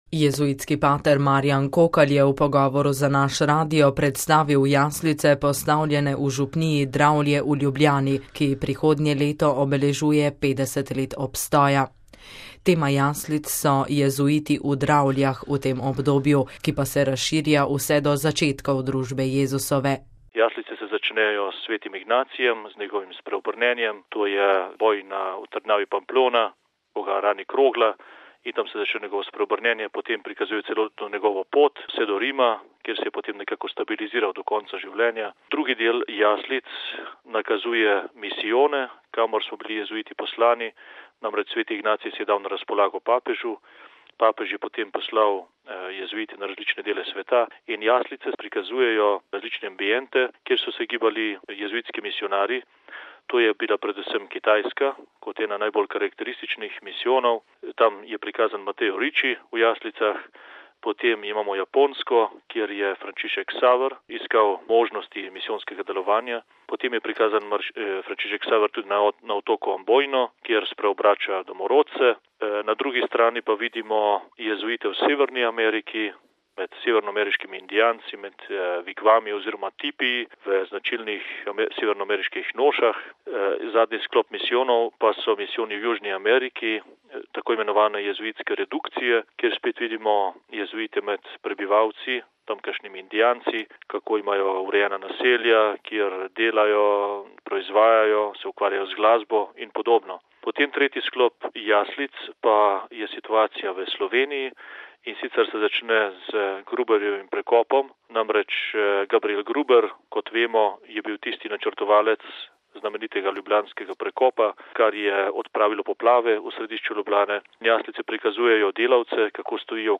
v pogovoru za naš radio